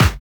Snares
AL_snr_mpc60.wav